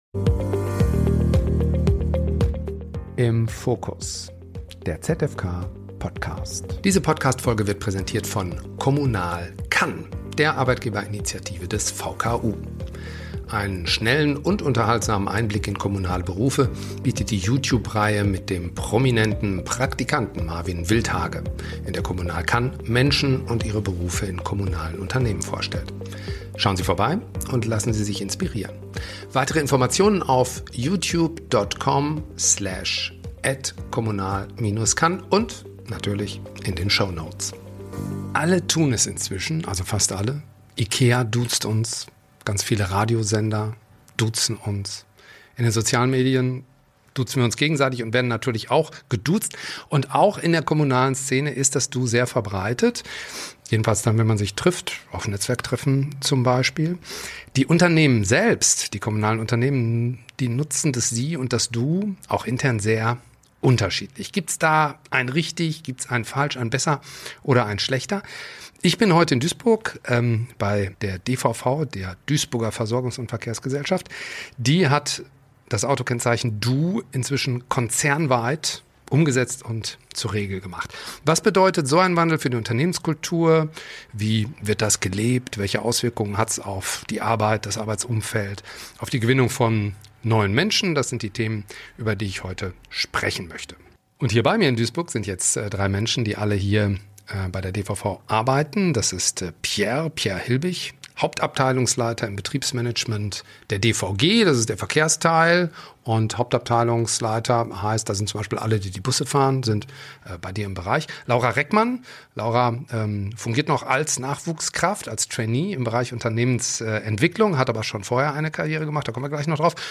Die drei Gäste berichten offen über ihre ganz persönlichen Eindrücke vom kulturellen Wandel innerhalb der DVV. Sie erzählen, wie das „Du“ Nähe schafft, Barrieren abbaut und den Umgang im Unternehmen spürbar erleichtert – aber auch, warum Freiwilligkeit und Fingerspitzengefühl dabei eine große Rolle spielen.